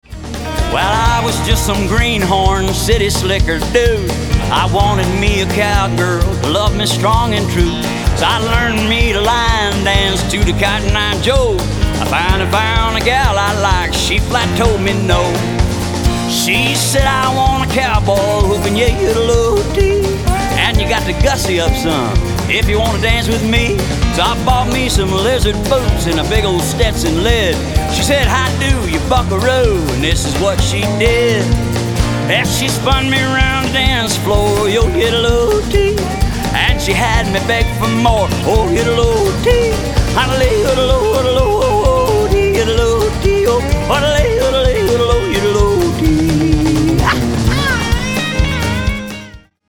--cowboy songs and folk music